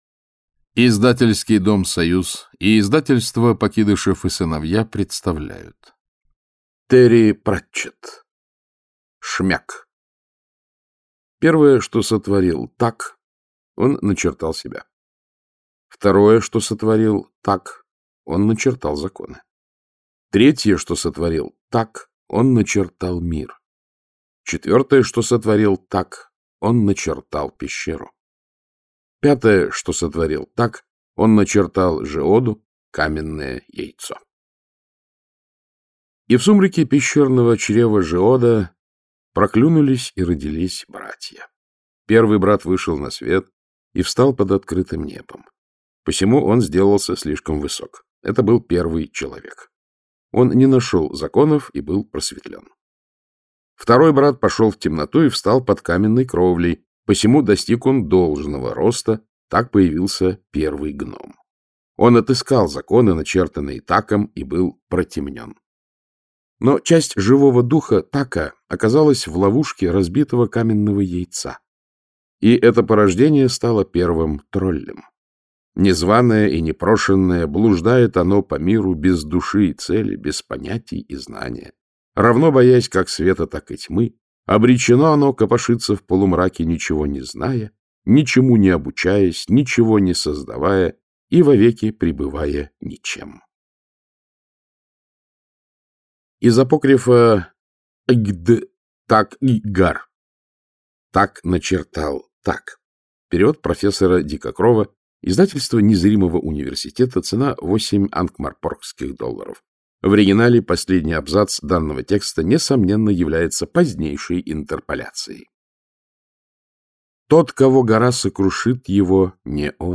Автор Терри Пратчетт Читает аудиокнигу Александр Клюквин.